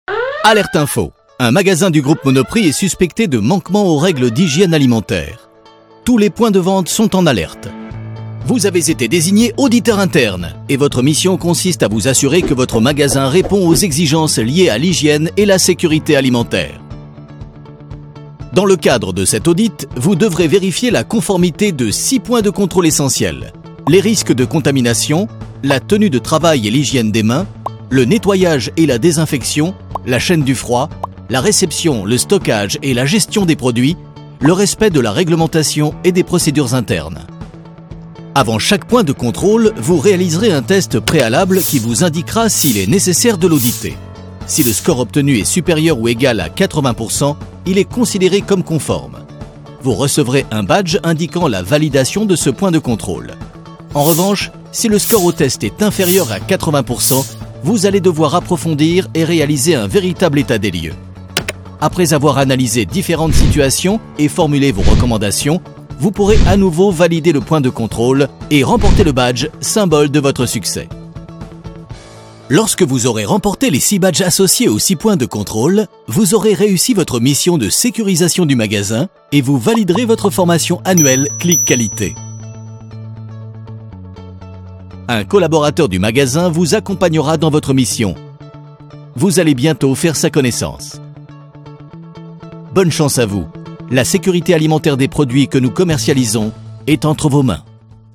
Male
Corporate, Assured, Friendly, Sarcastic, Smooth, Warm
Microphone: Neumann U87
Audio equipment: Manley voxbox , avalon , RME Fire face